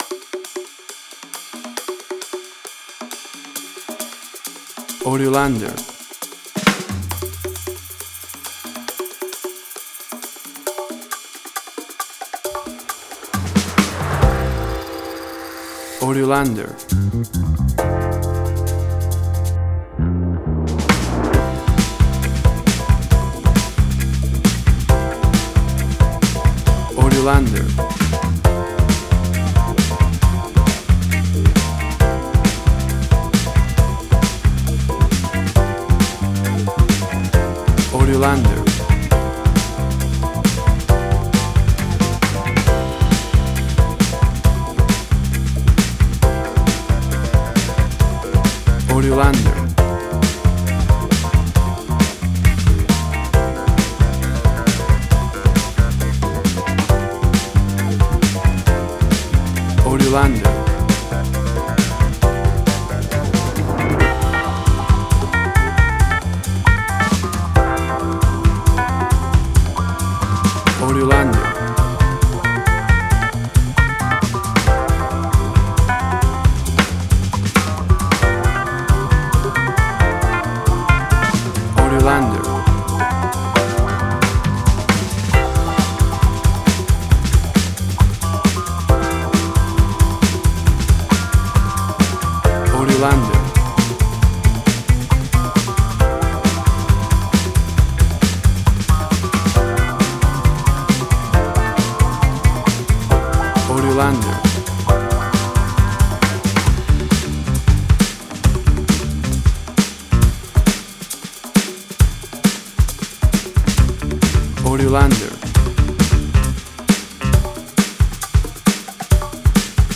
Tempo (BPM): 135